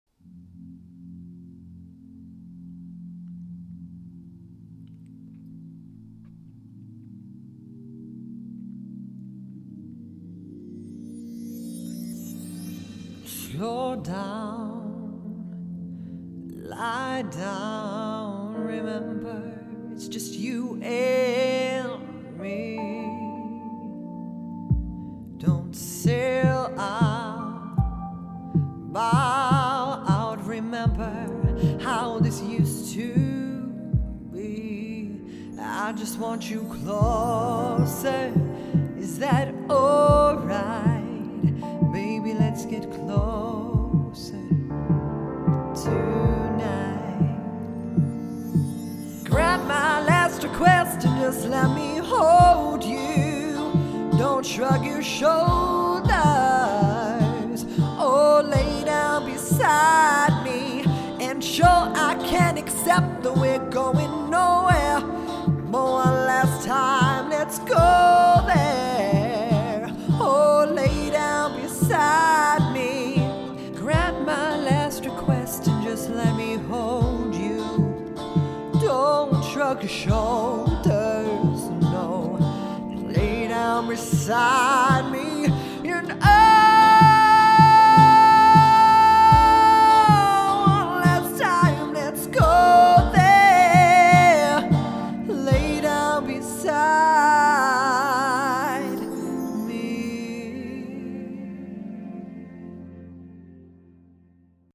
Singing Showreel
A versatile voice actor who is confident working with many voices, accents and even languages, as one of only voice over artists that is also fluent in British Sign Language! Full of fun and bursting with energy, he also has a strong second tenor singing voice.
Male
Bright
Friendly